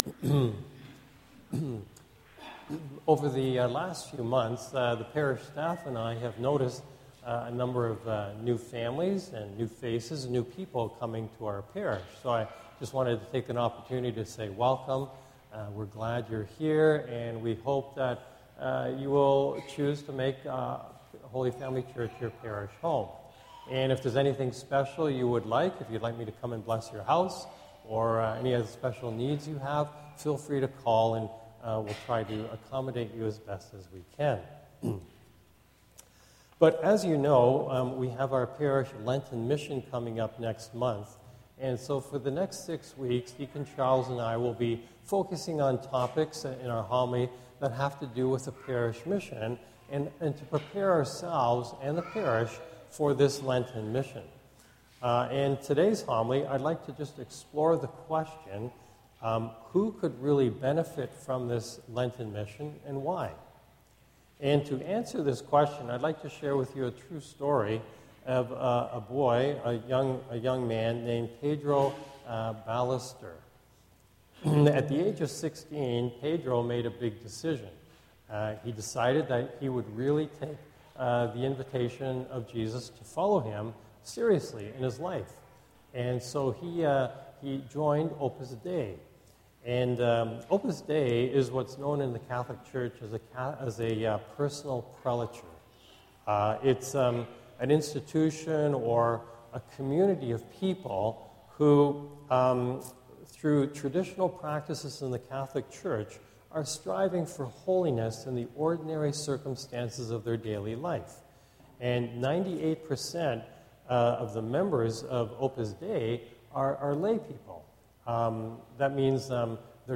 Homily
recorded at Holy Family Parish